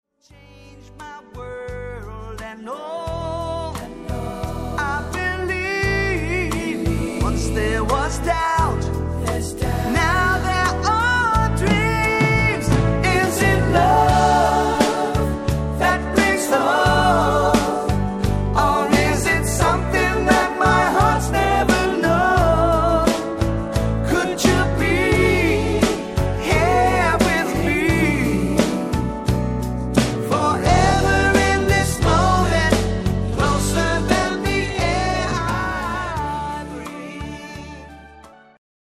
フリー・ソウル・バンド